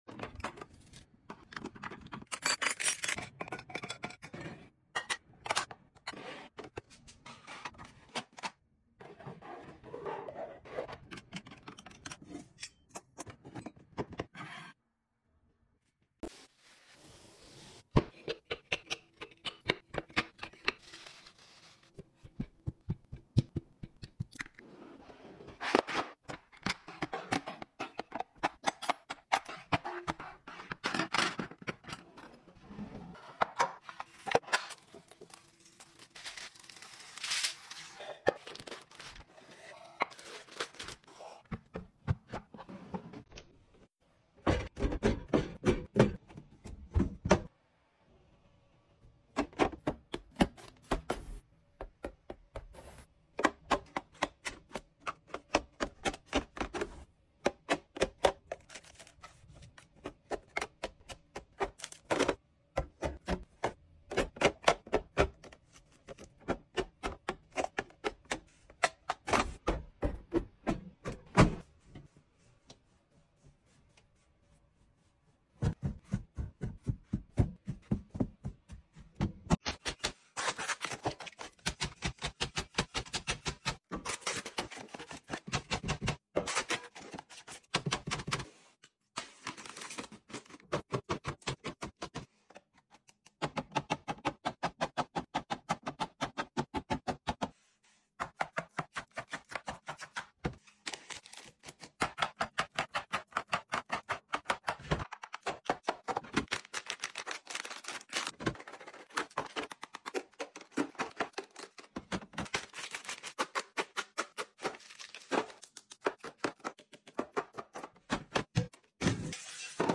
Asmr kitchen restocking, organizing and